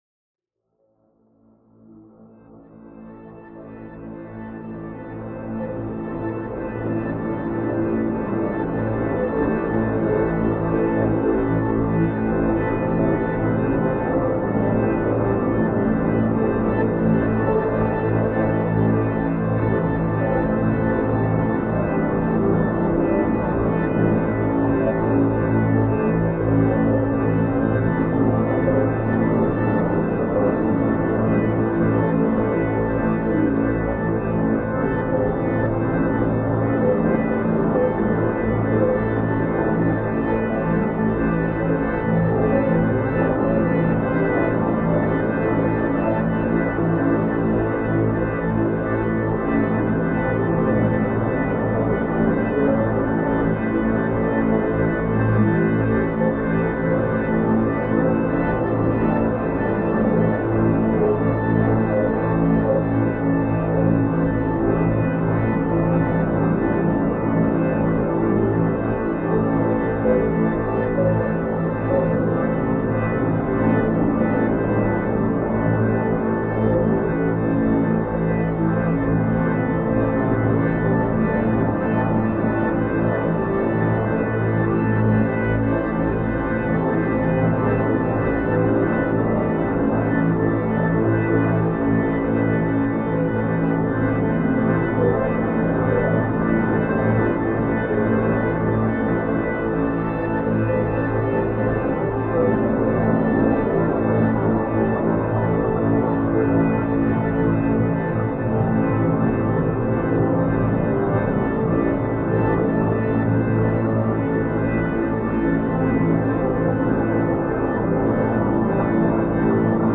Genre: Drone.